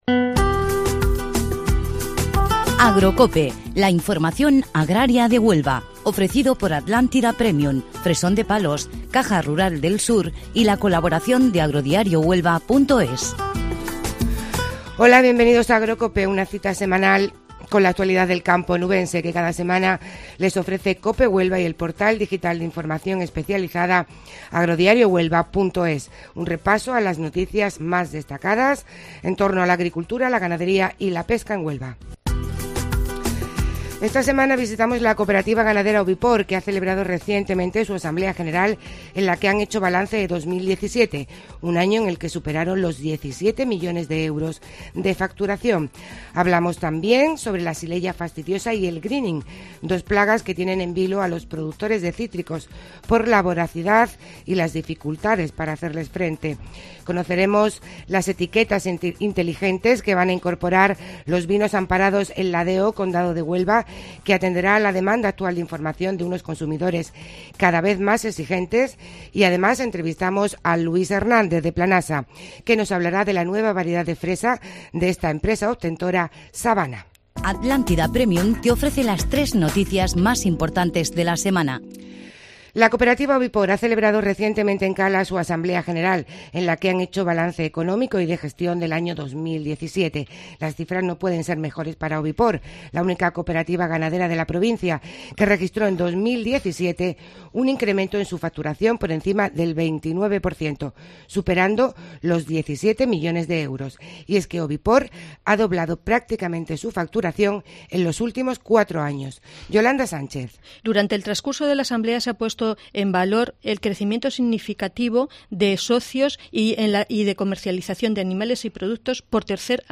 Hablamos sobre la xylella fastidiosa y el greenning, dos plagas que tienen en vilo a los productores de cítricos por la voracidad y las dificultades para hacerles frente. Conoceremos las etiquetas inteligentes que van a incorporar los vinos amparados en la DO Condado de Huelva que atenderá la demanda actual de información de unos consumidores cada vez más exigentes. Y entrevistamos